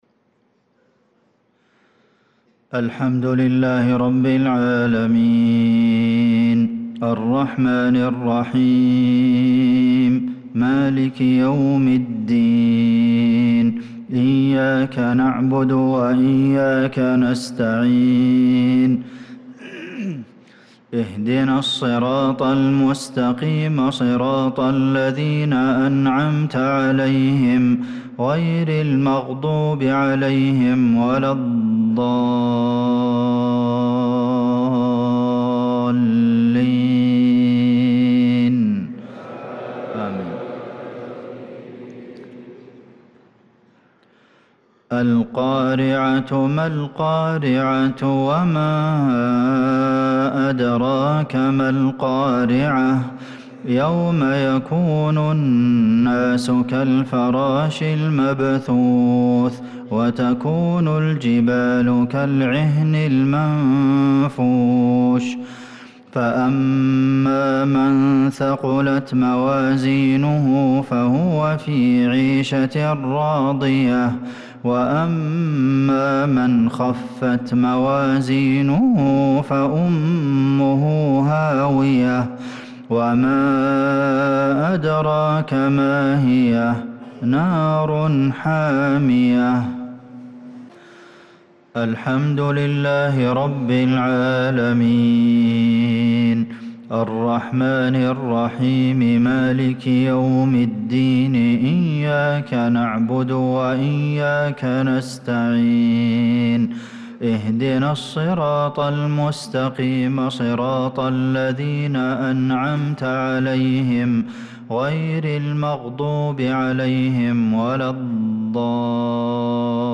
صلاة المغرب للقارئ عبدالمحسن القاسم 11 شوال 1445 هـ
تِلَاوَات الْحَرَمَيْن .